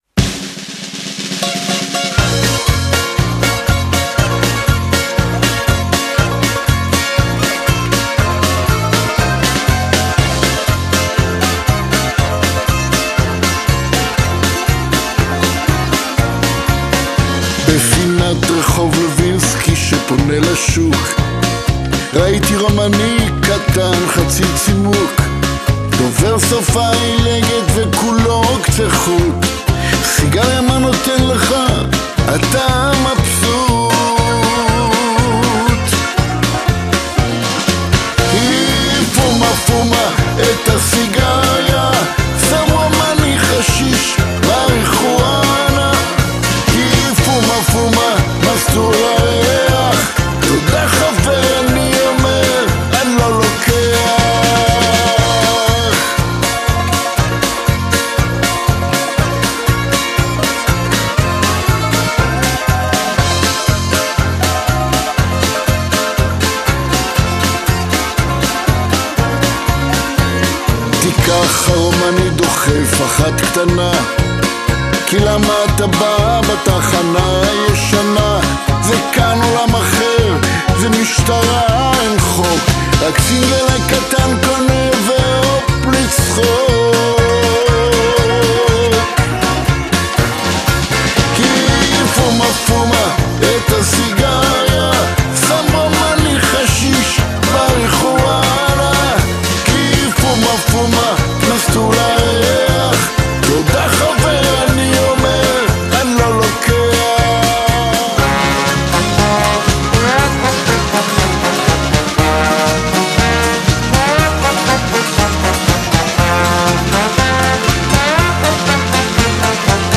תופים וקלידים
גיטרות